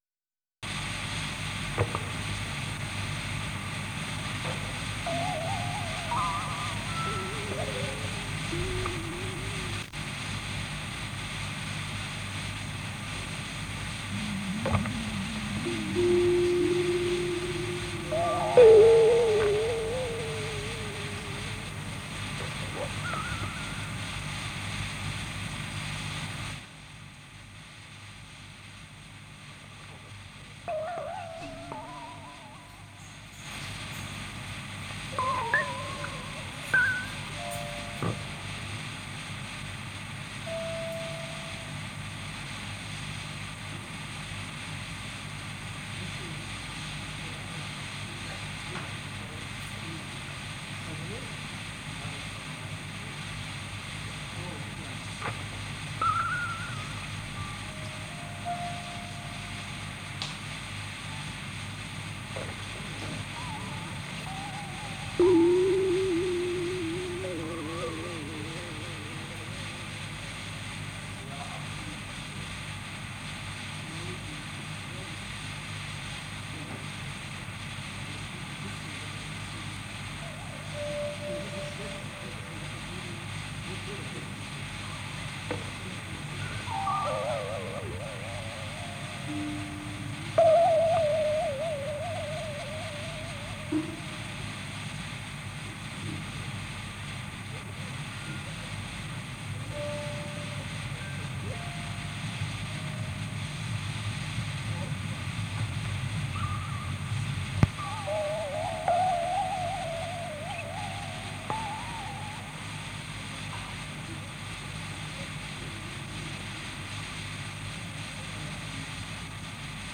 2020-06-21 Spieluhr_Tape (Tascam-Soundcheck).flac
Das Tonband läuft über die Spule des Uher hinaus in das Grundig Tonband und ist über die Lautsprecher mit circa 1min15s/37s Verzögerung zu hören.
Über den Mixer Ausgang (ballanced) nimmt Tascam HD-P2 auf.
Die Abspielgeschwindigkeit des Uher Royal Deluxe ist 4,7 cm/s.
2020-06-21 Spieluhr/Tape (elektro akustisch) (live) Audio-Playlist: 2020-06-21 Spieluhr-Tape (elektro akustisch) (live).m3u8 Youtube Video Playlist: 2 Spieluhren (neue Resonanz) (Audio + Komposition) sonntag, 21.